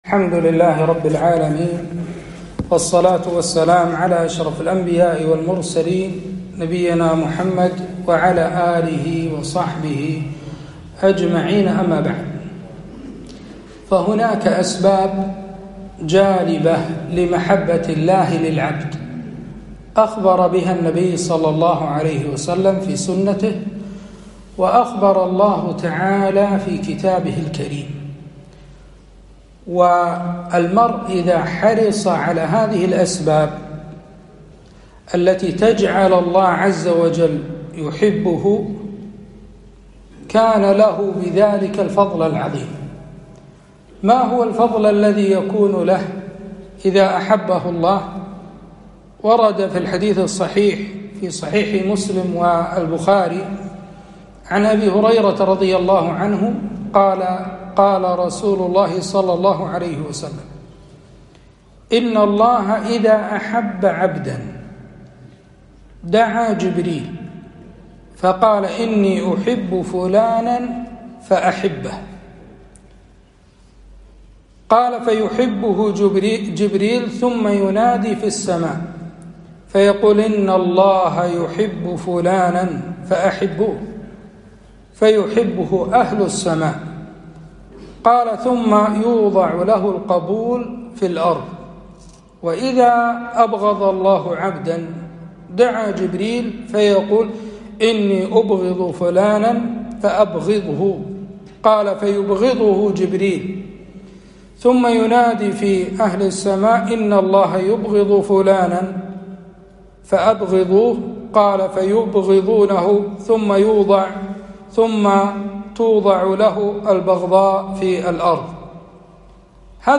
كلمة - الأسباب الجالبة لمحبة الله عز وجل